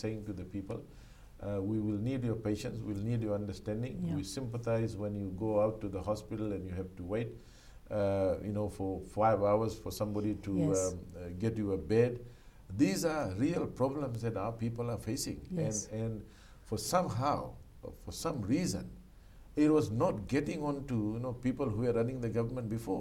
Speaking on Saqamoli Matters last night, Prasad says it’s just been six months since the coalition government came into power and they haven’t been able to fix many things in the health sector.
Minister for Finance, Professor Biman Prasad.